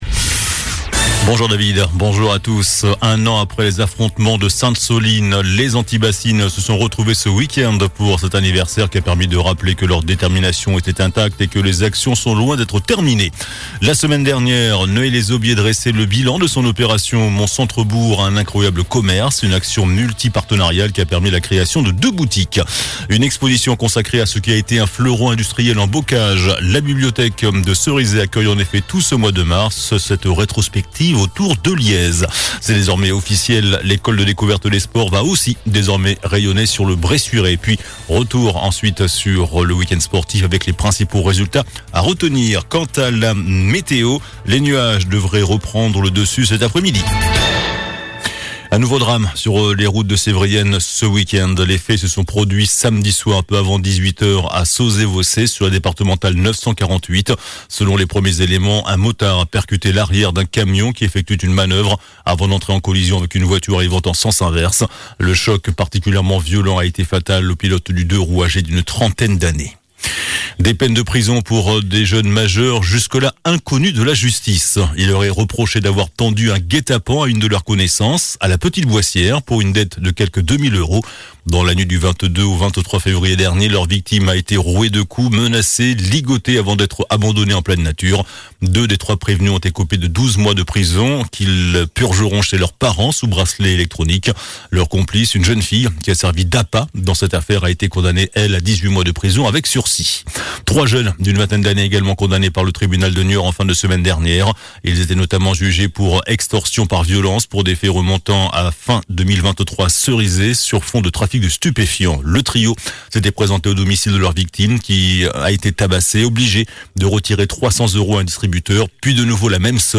JOURNAL DU LUNDI 25 MARS ( MIDI )